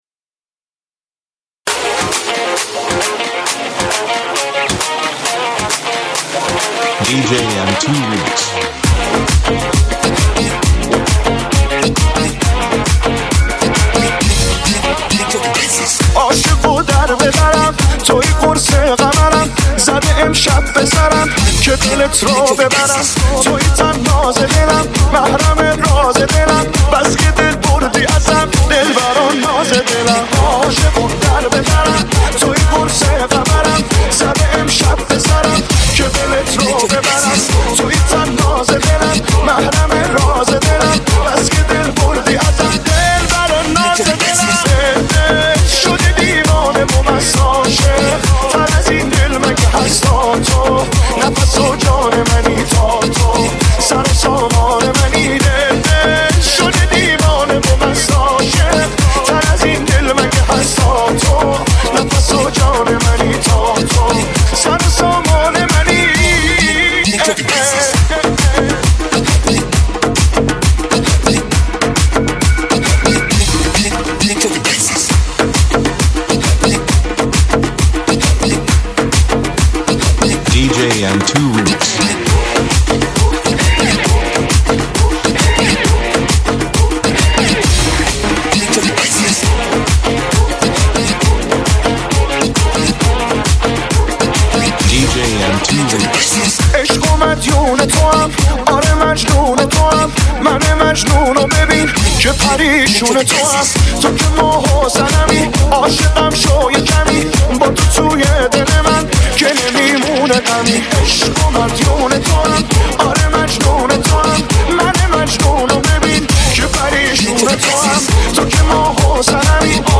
دانلود آهنگ شاد با کیفیت ۱۲۸ MP3 ۳ MB